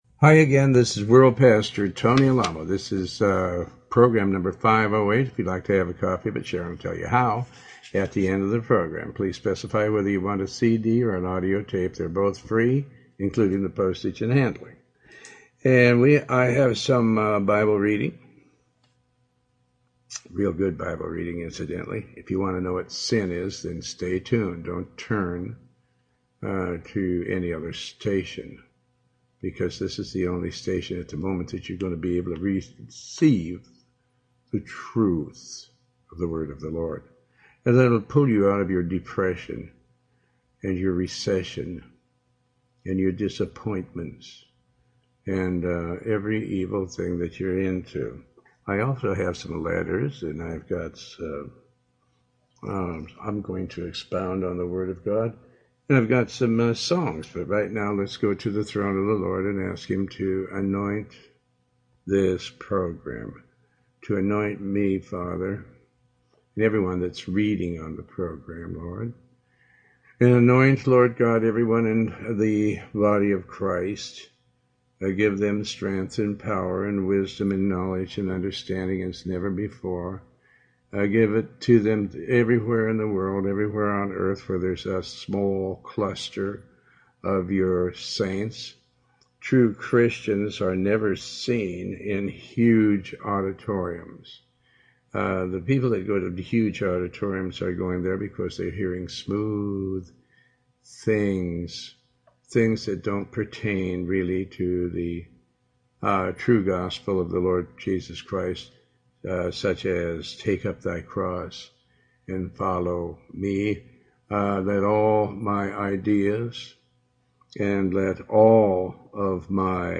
Talk Show Episode, Audio Podcast, Tony Alamo and Program 508 on , show guests , about Faith,pastor tony alamo,Tony Alamo Christian Ministries,Bible Study,sermon, categorized as Health & Lifestyle,History,Love & Relationships,Philosophy,Psychology,Christianity,Inspirational,Motivational,Society and Culture